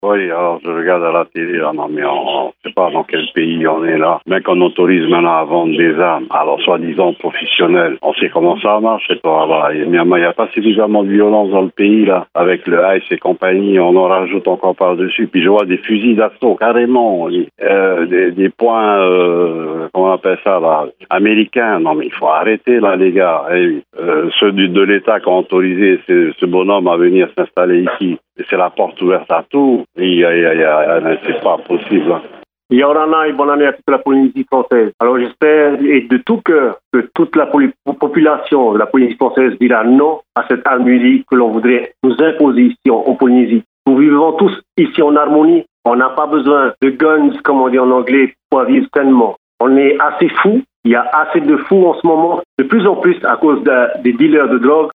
Répondeur de 6:30, le 18/01/2021